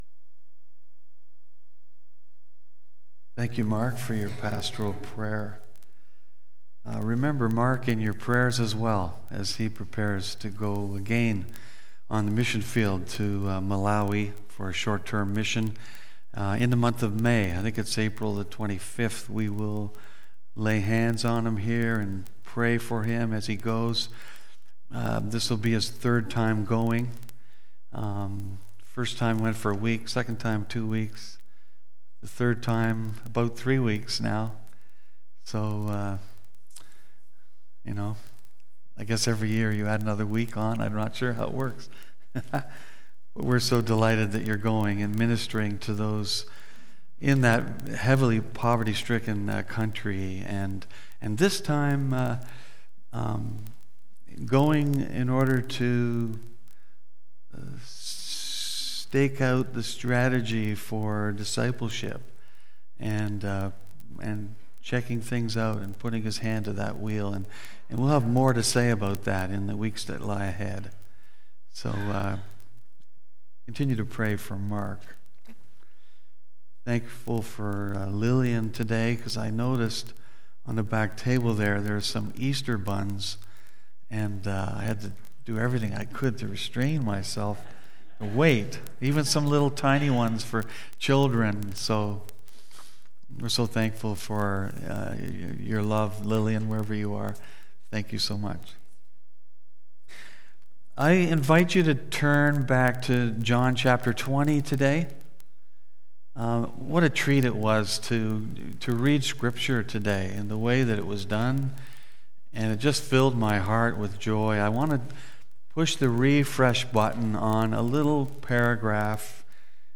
BACK TO SERMON LIST Preacher